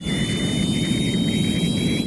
Shipmove1.wav